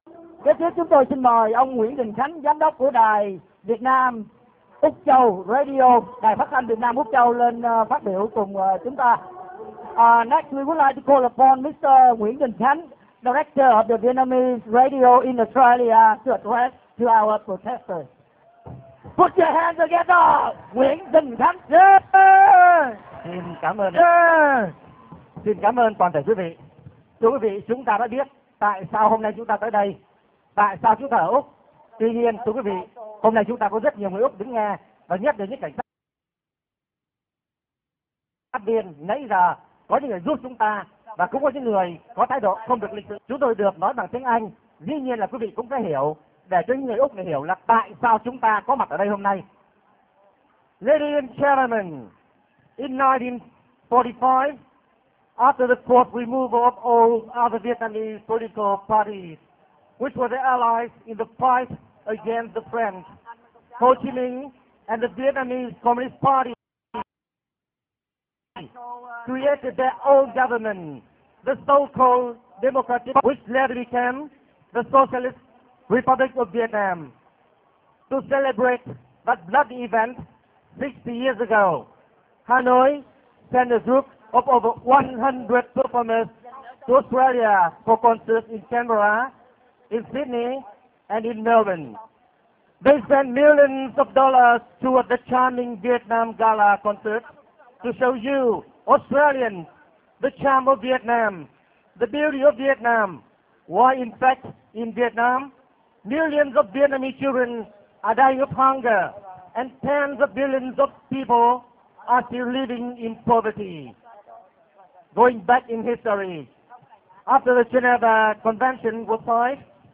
Quư vị đang nghe phần 3 trong bài trực tiếp truyền thanh qua Paltalk của đồng bào biểu t́nh tại Sydney